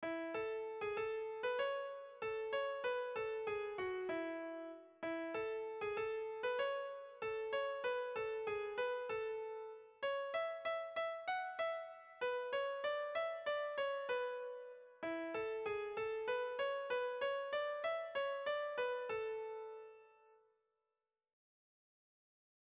Irrizkoa
Zortziko txikia (hg) / Lau puntuko txikia (ip)
A1A2BA3